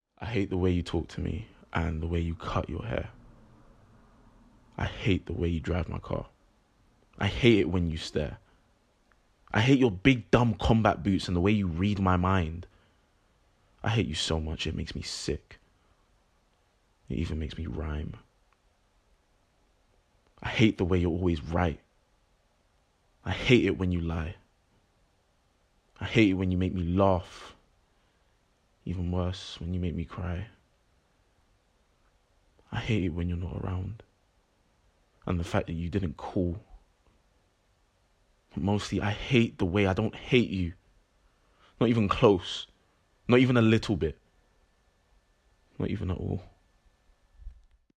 Deep, Relaxed, Urban, Matey